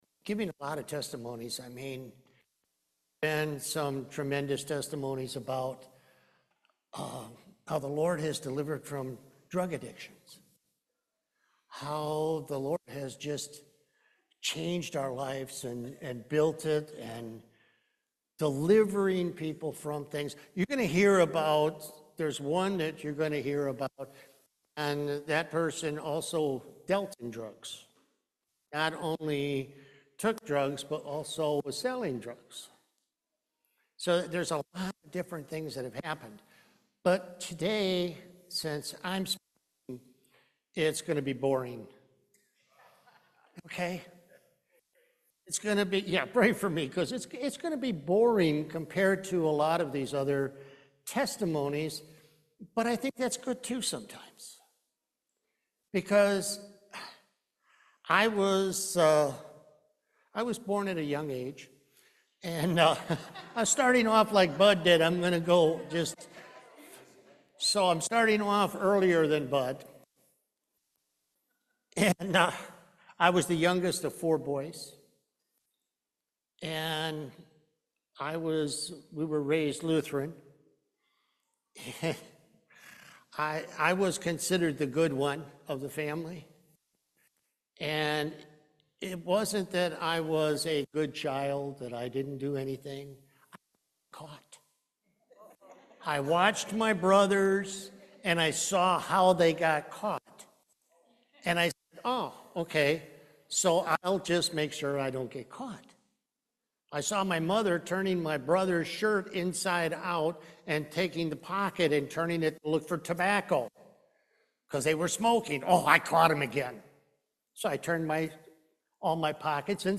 Series: Testimony
2 Timothy 3:16 Service Type: Main Service He needs to be the most important thing in our lives.